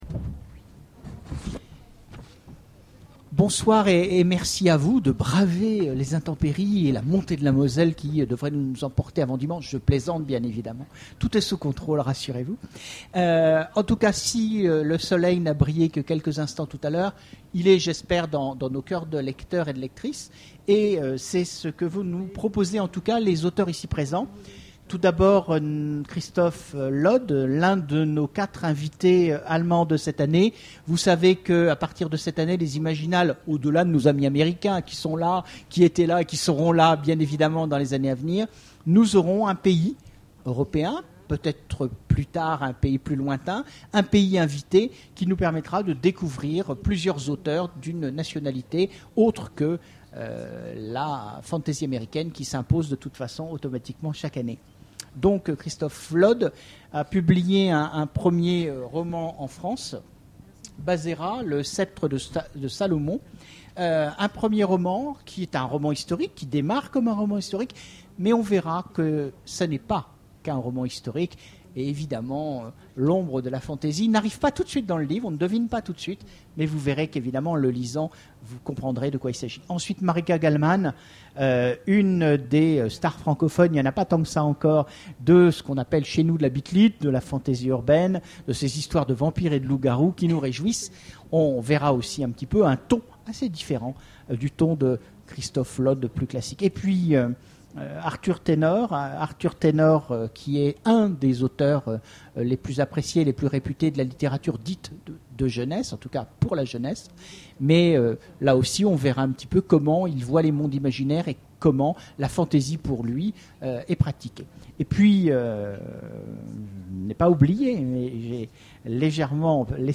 Imaginales 2013 : Conférence Le bonheur de raconter